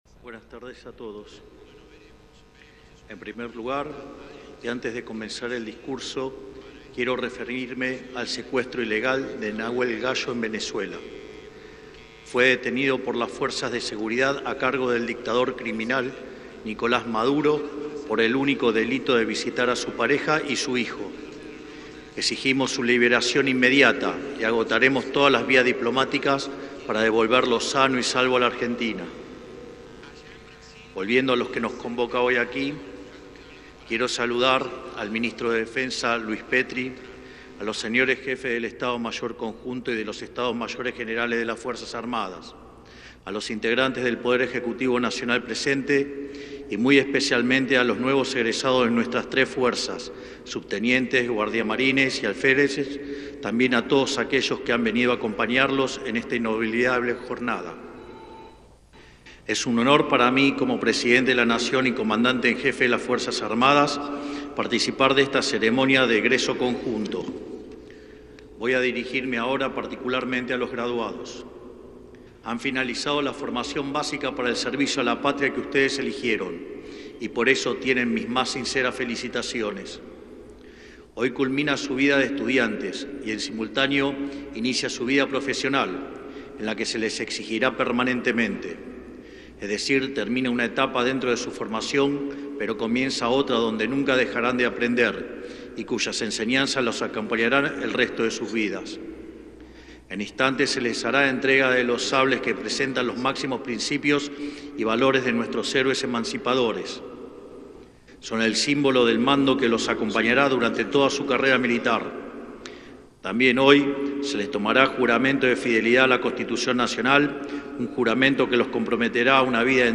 Milei-en-la-entrega-de-sables-y-medallas-en-el-Colegio-Militar.mp3